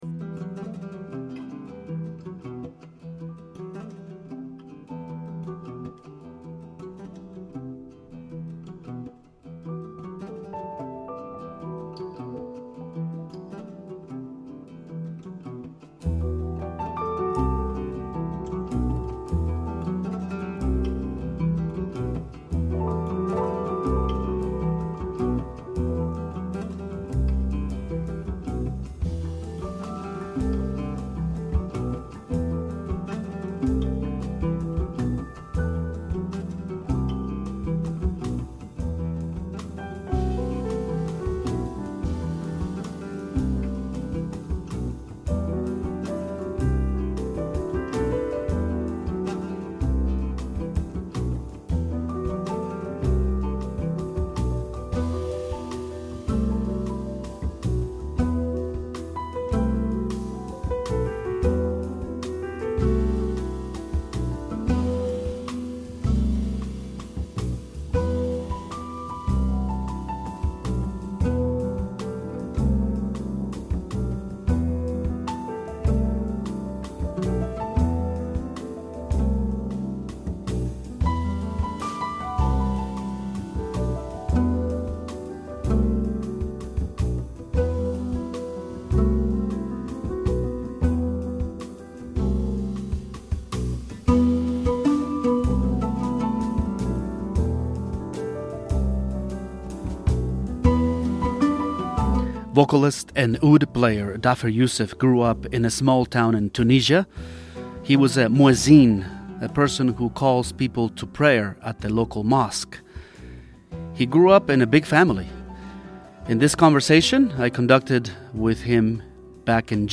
Interview with Dhafer Youssef